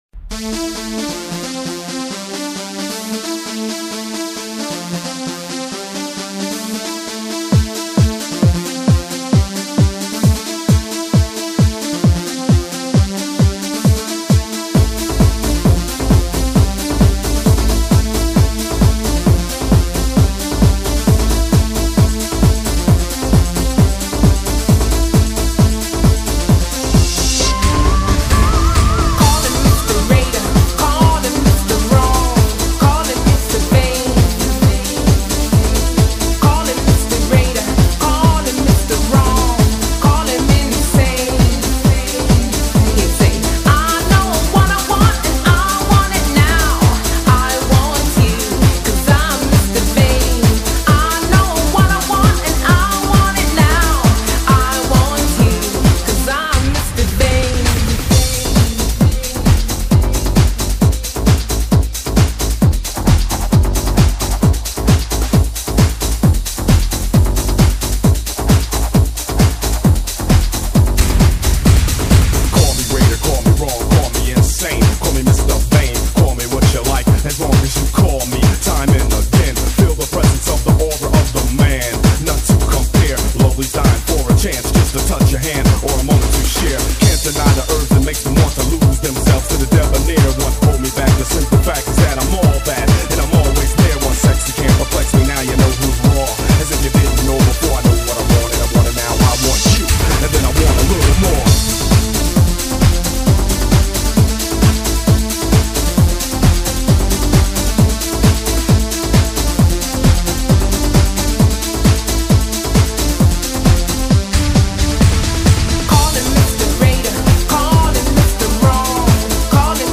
песня суперская,приятная мелодия и молодежныи стиль